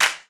T.I. Clap.wav